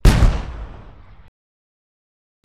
cannon_1.wav